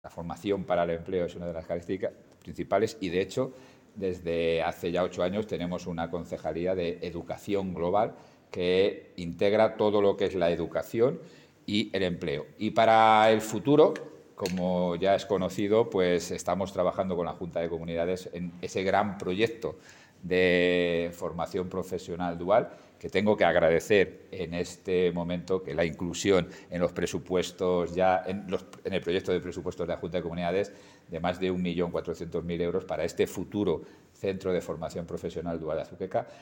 José Luis Blanco. Clausura programa Recual 2023